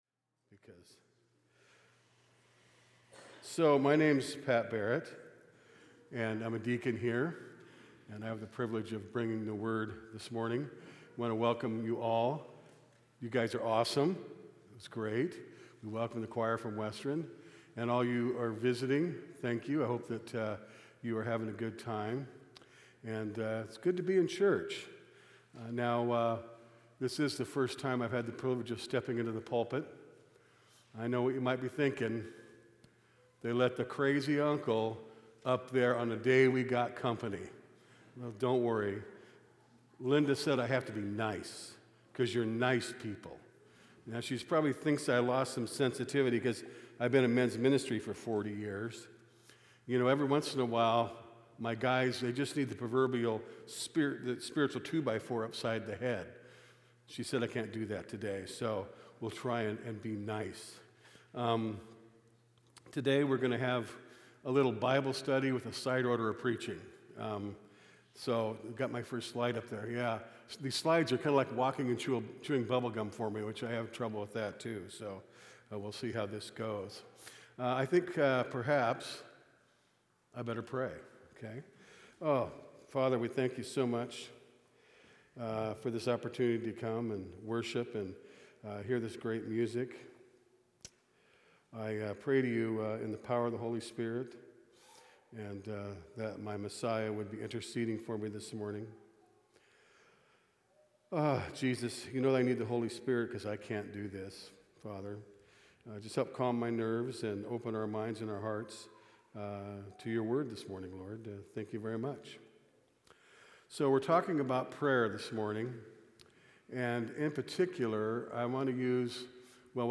Sermons | Salt Creek Baptist Church